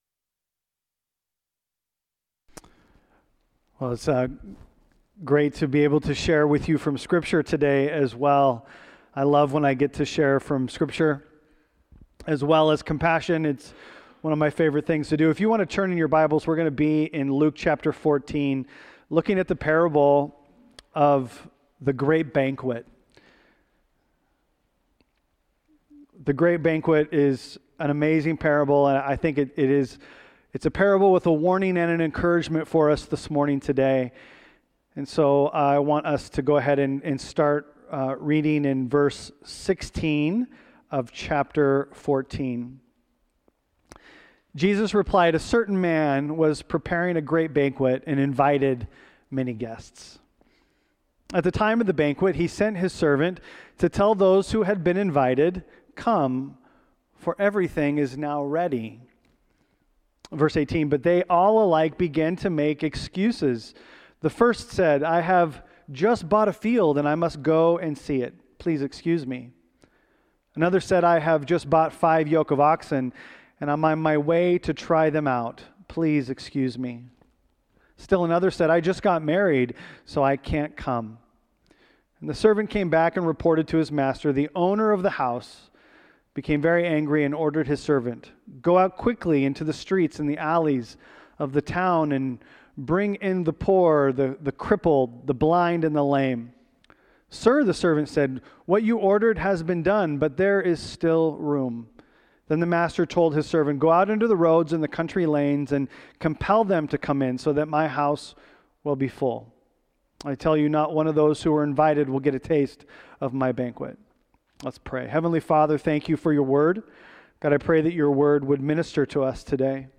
Luke 14:15-24 Part sermon only available.